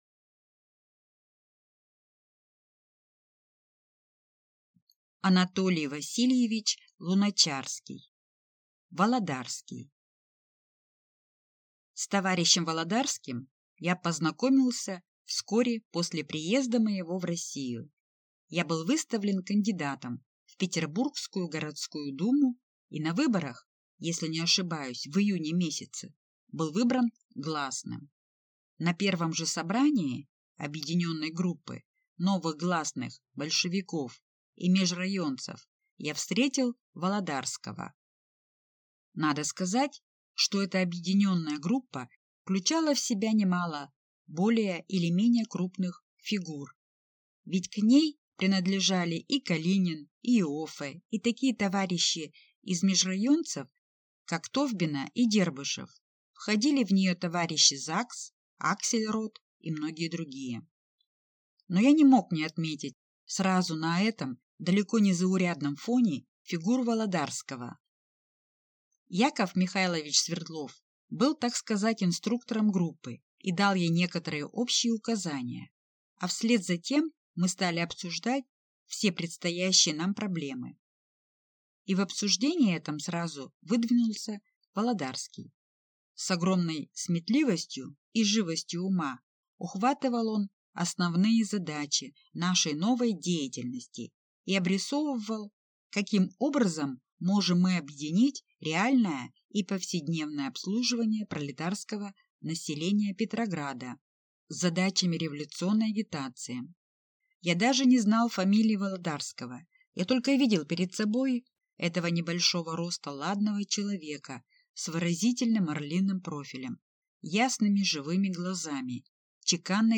Аудиокнига Володарский | Библиотека аудиокниг